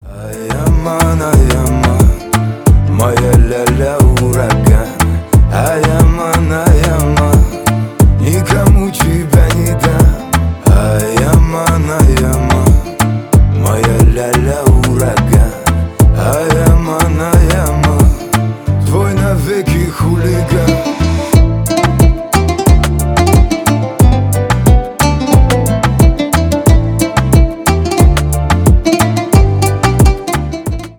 кавказские
поп
рэп